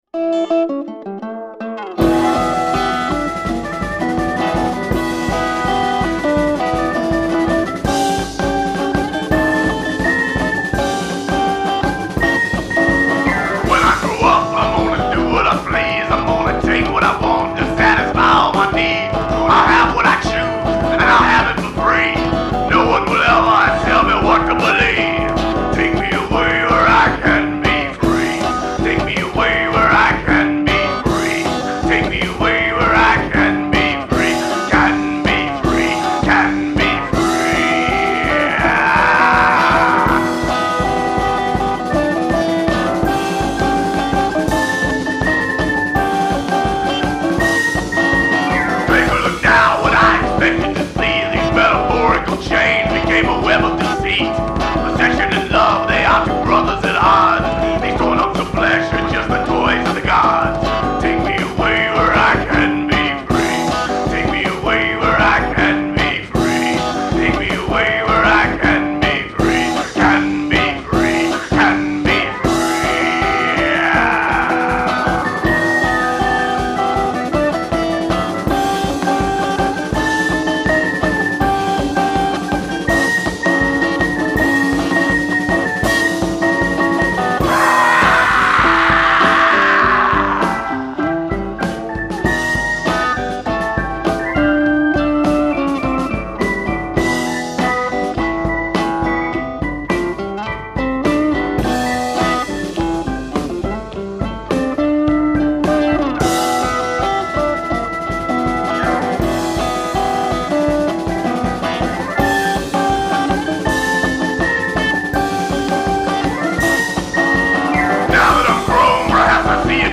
church organ